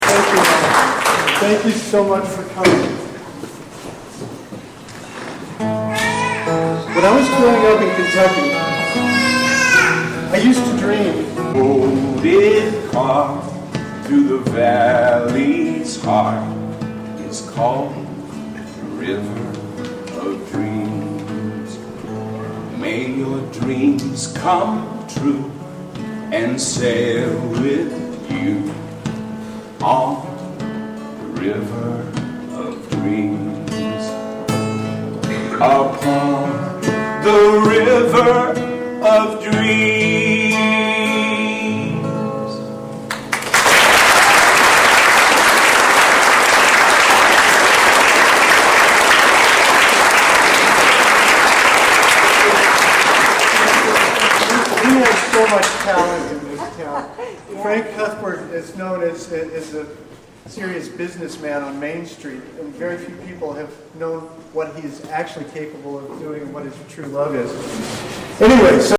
Live radio show "WGXC Online Radio Night" hosted b...